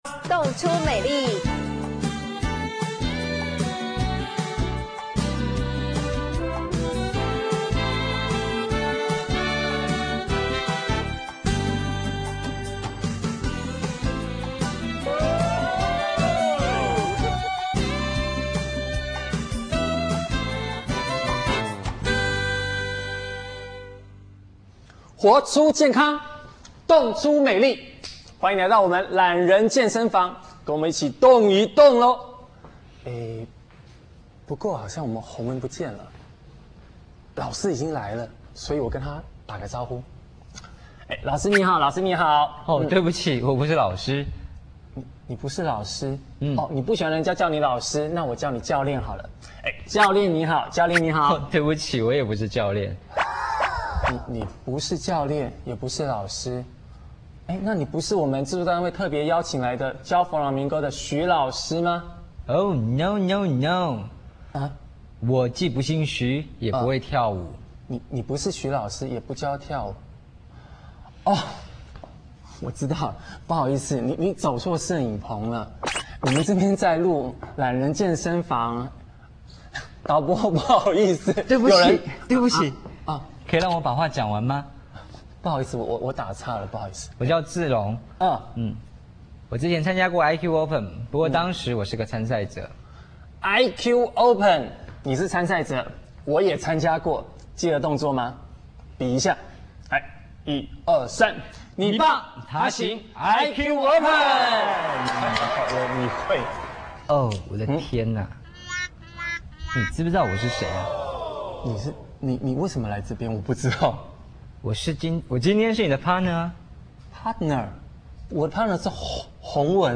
華麗的舞衣、充滿原始生命力的表演、濃郁民族色彩的吟唱、 動人心絃的吉他聲、節奏分明的響板，透過舞者扭腰、甩頭、踱步…強烈傳達情感與活 力，總能喚起欣賞者心中的悸動。